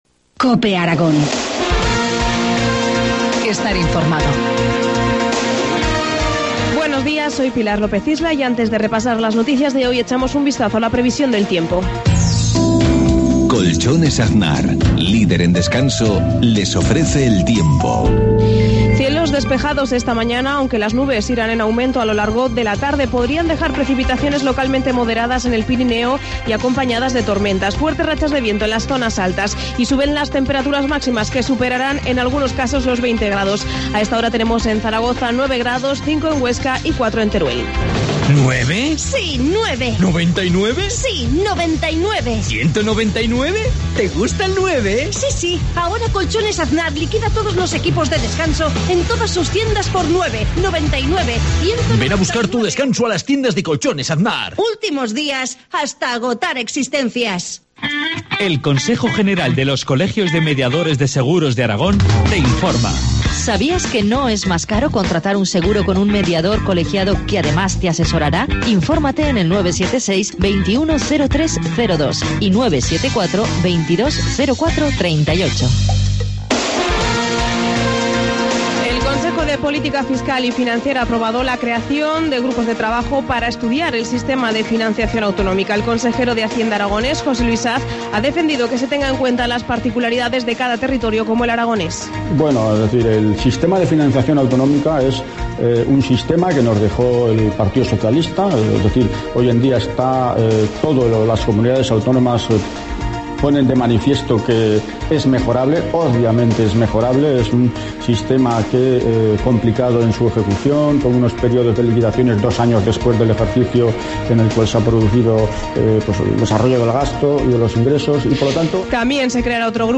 Informativo matinal, viernes 22 de marzo, 7.53 horas